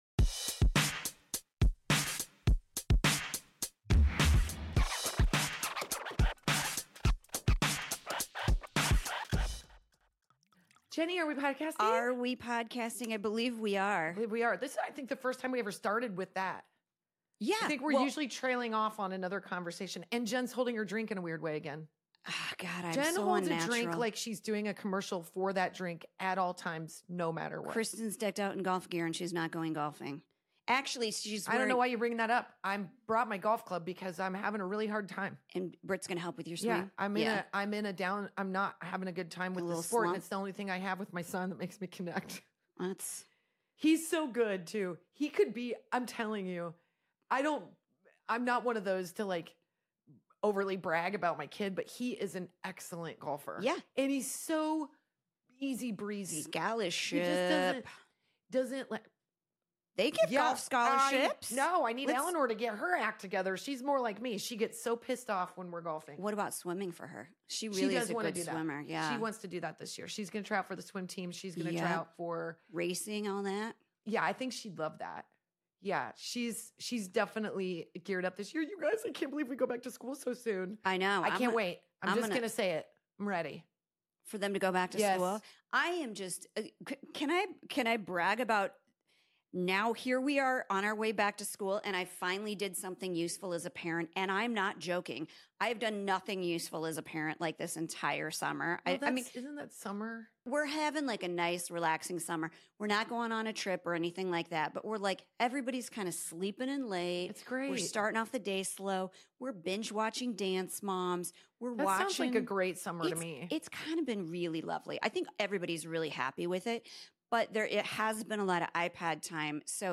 Female comedy duo